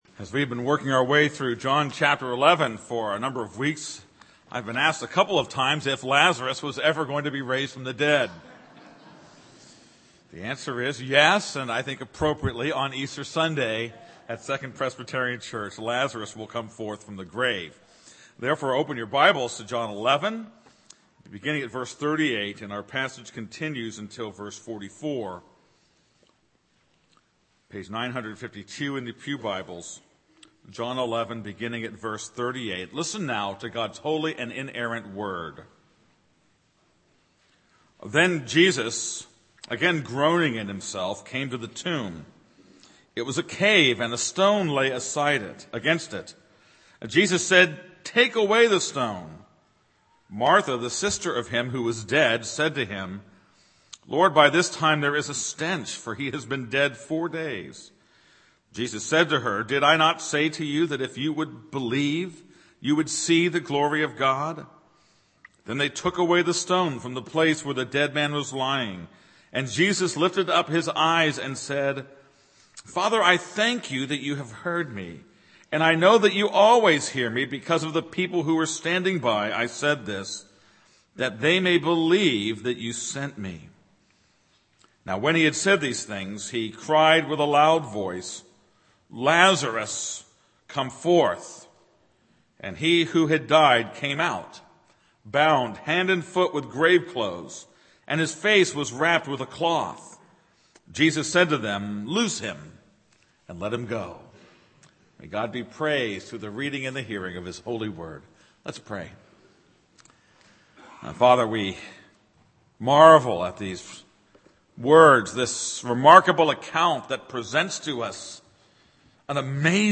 This is a sermon on John 11:38-44.